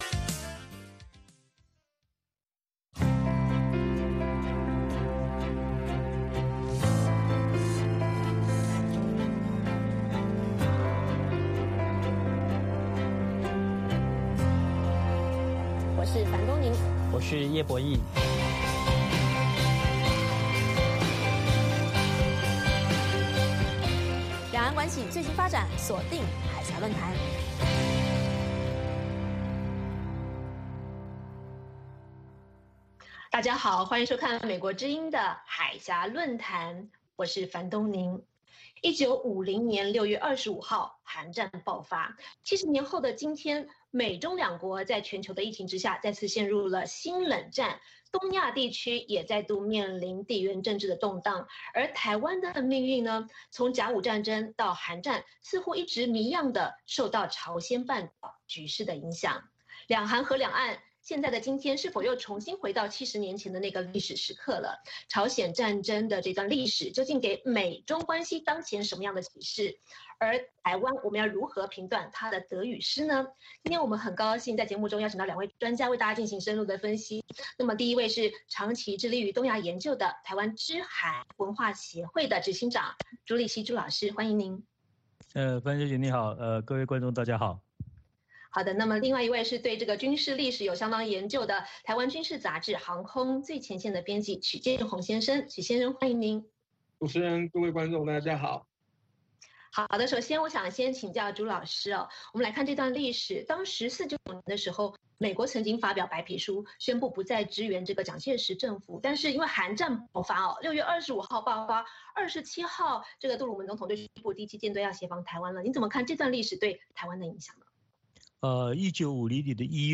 北京时间下午5-6点广播节目。广播内容包括新动态英语以及《时事大家谈》(重播)